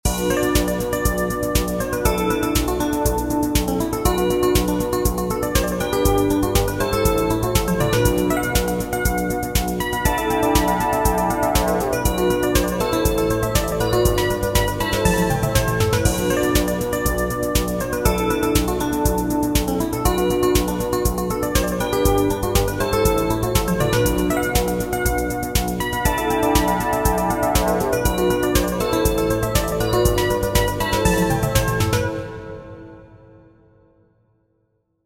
7edo disco in B neutral
7edo_demo.mp3